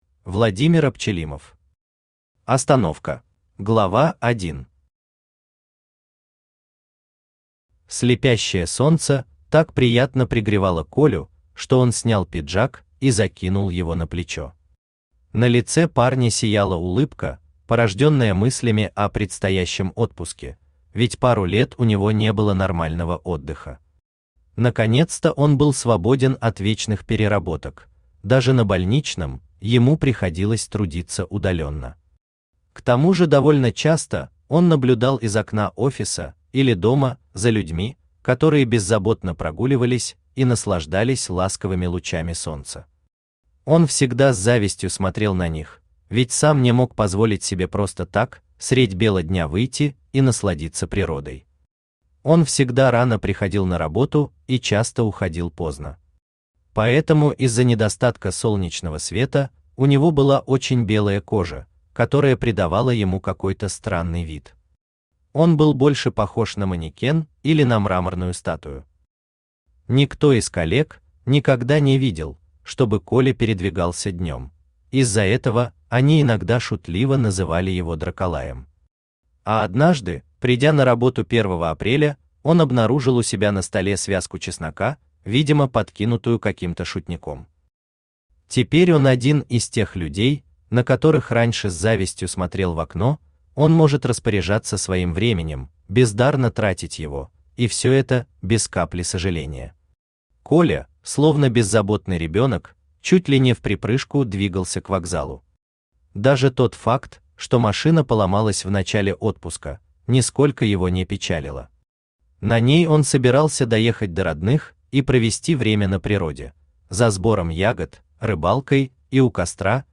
Аудиокнига Остановка | Библиотека аудиокниг
Aудиокнига Остановка Автор Владимир Владимирович Апчелимов Читает аудиокнигу Авточтец ЛитРес.